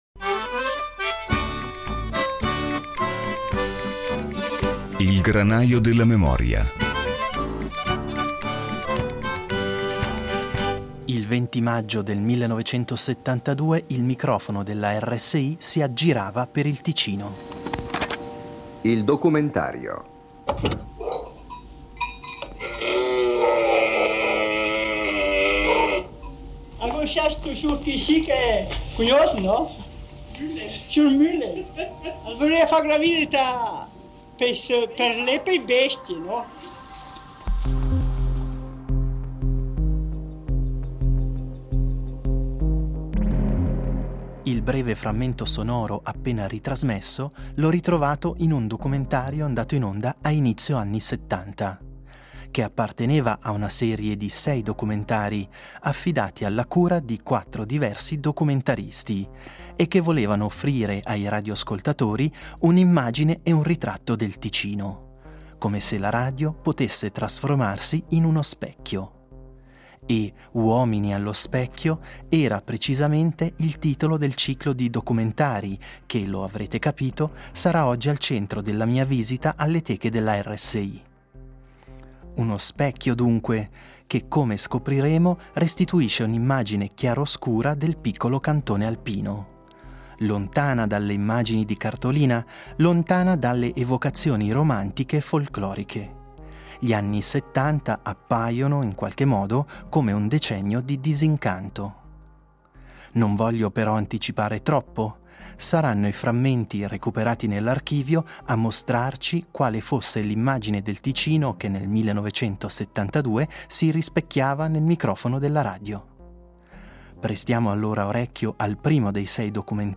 Un ciclo di sei documentari costituisce il materiale sonoro che “Il Granaio della memoria” utilizzerà per comporre il percorso d’archivio di questa settimana. Andati in onda nel 1972 e curati da una cordata di sei documentaristi, i documentari si proponevano di mettere il Ticino allo specchio, per confrontarlo con le proprie contraddizioni. Dall’operazione radiofonica affiora un ritratto chiaroscuro di un cantone confrontato con tutta una serie di problematiche: dallo spopolamento delle valli alla disaffezione per la cosa pubblica.